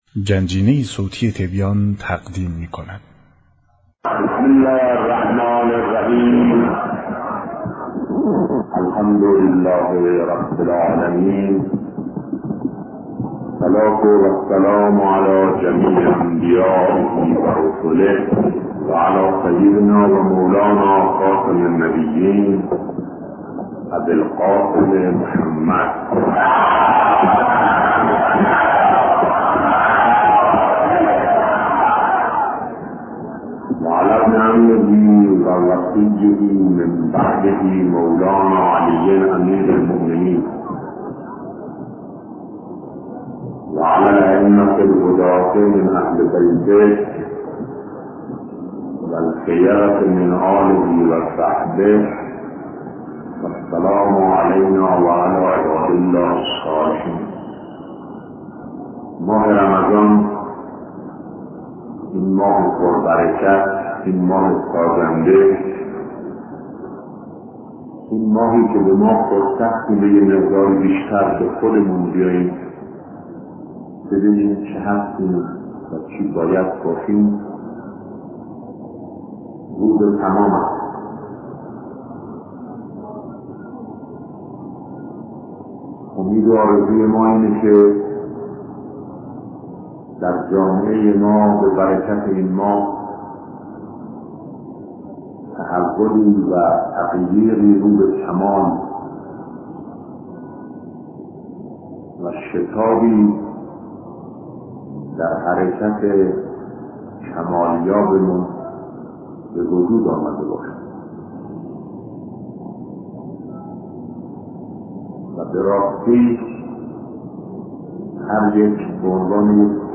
سخنرانی شهید بهشتی در ماه مبارک رمضان-با موضوع انسان موجودی در مسیرِ شدن